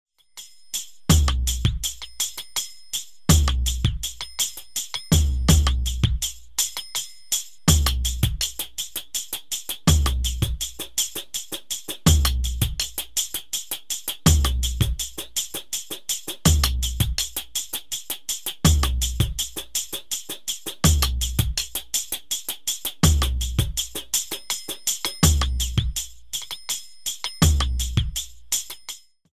CD for Modern Dance Class